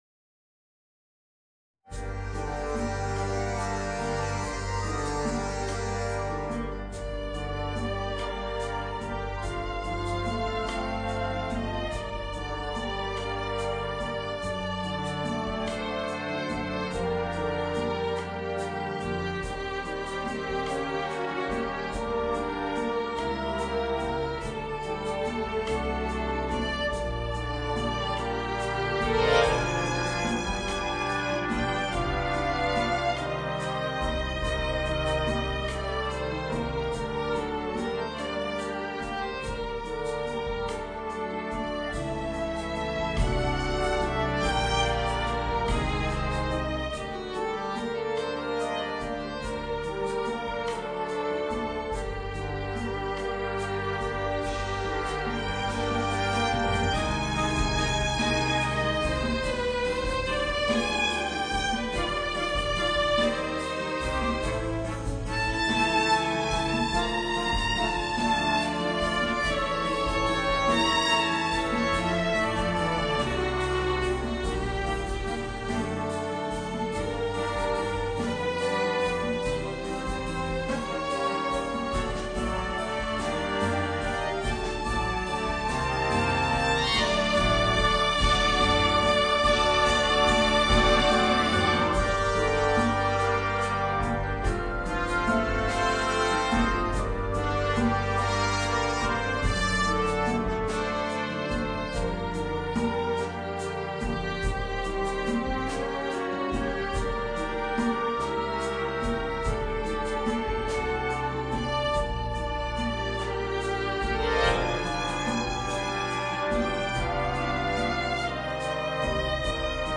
Voicing: Violin and Concert Band